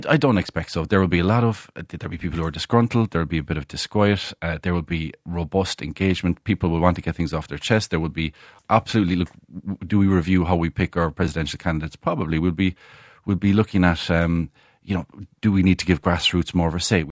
Fianna Fail Junior Minister Chris O’Sullivan is expecting a tense meeting, but doesn’t think there will be a leadership challenge….